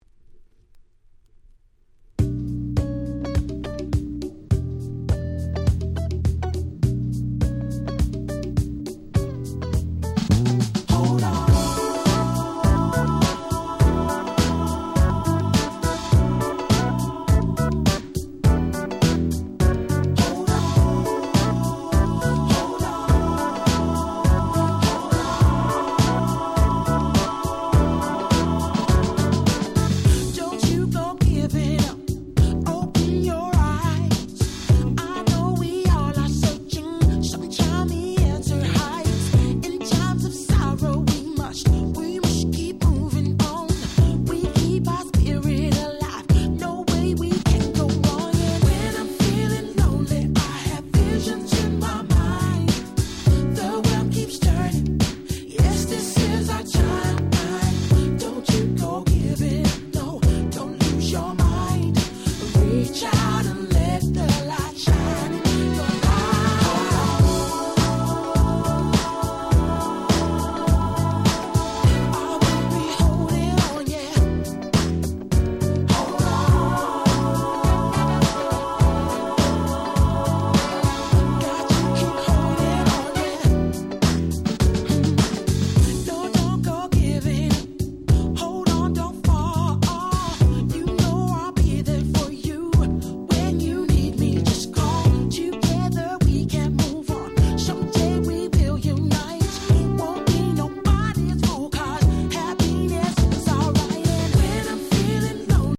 UK R&B Classic LP !!